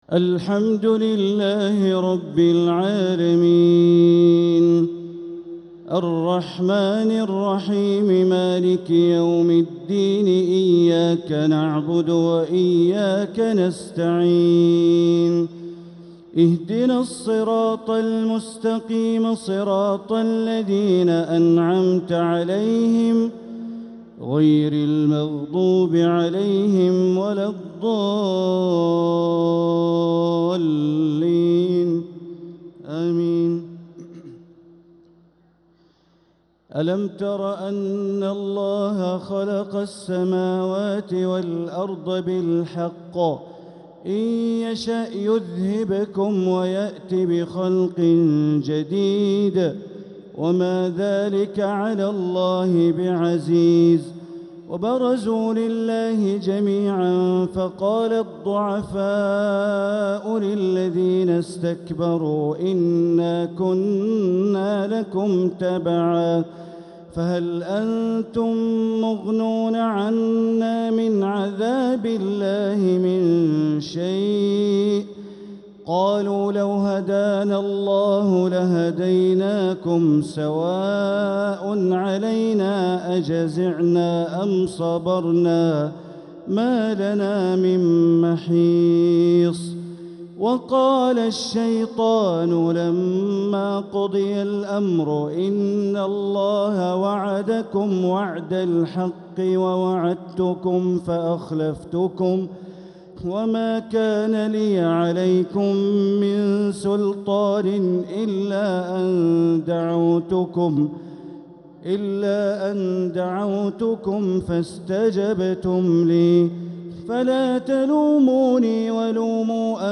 Taraweeh 18th night Ramadan 1446H Surat Ibrahim and Al-Hijr > Taraweeh Ramadan 1446H > Taraweeh - Bandar Baleela Recitations